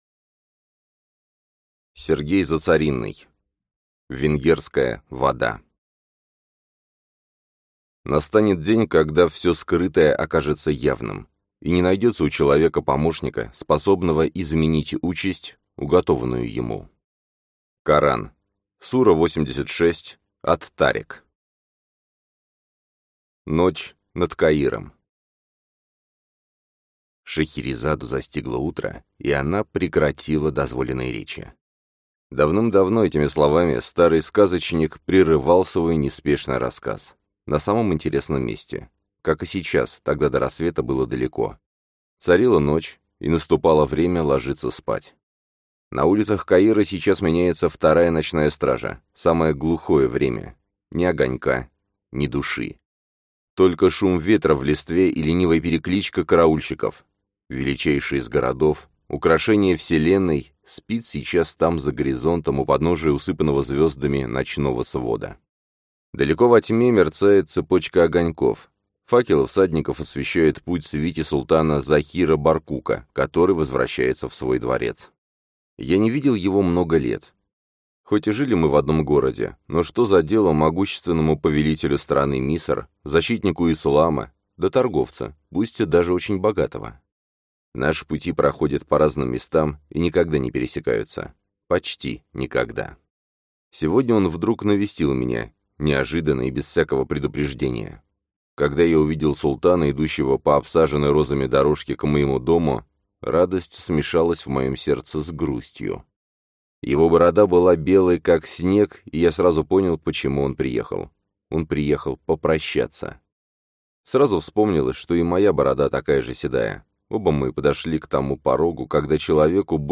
Аудиокнига Венгерская вода | Библиотека аудиокниг